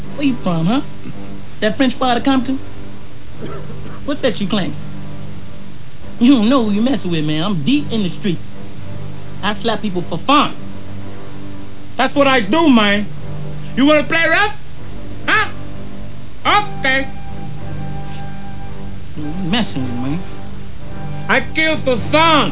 Chris Tucker doing his impression of Tony Montana from Scarface.